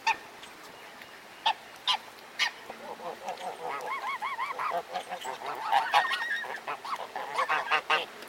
Category: Bird Ringtones